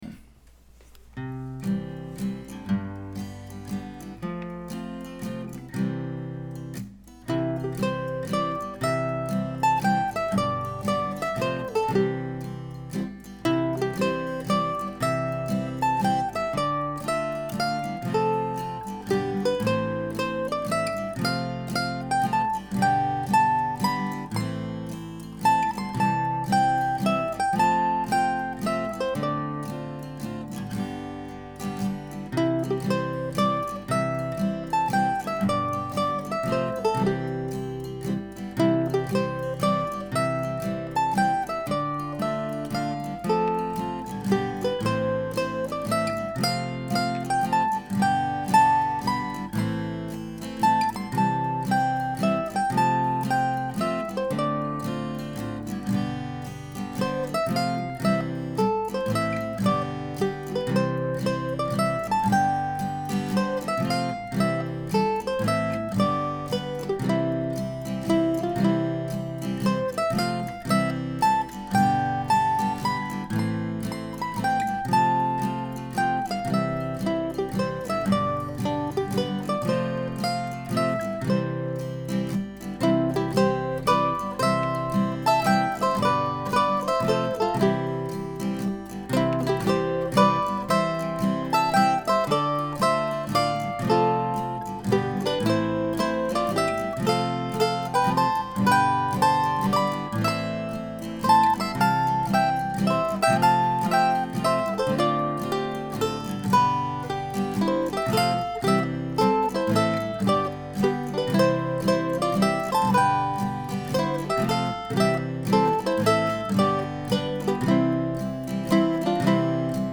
Another waltz in C major. I recorded this a little on the slow side.